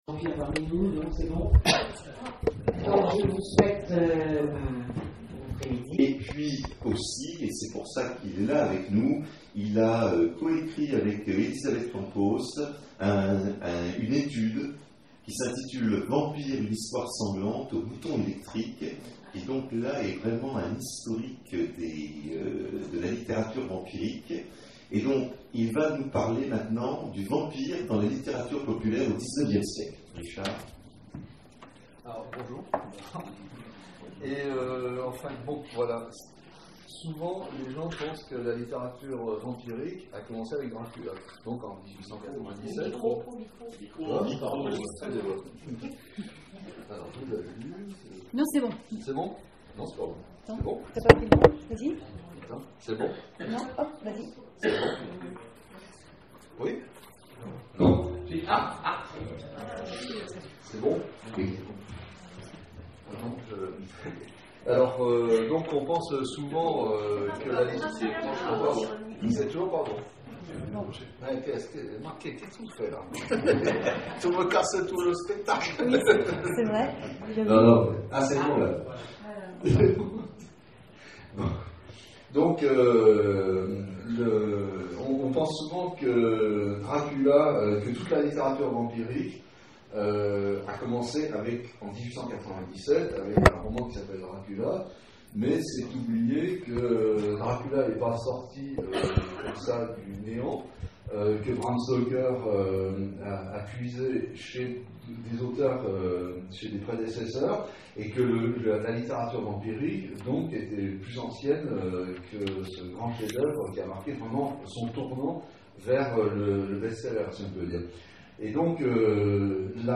Salon du roman populaire d'Elven : conférence Le vampire dans la littérature populaire au 19e siècle